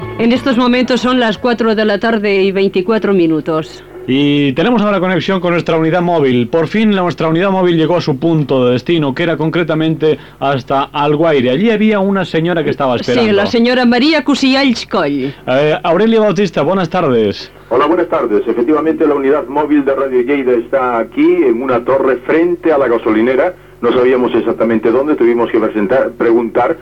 Hora, connexió amb la unitat mòbil de l'emissora que està a la localitat d'Alguaire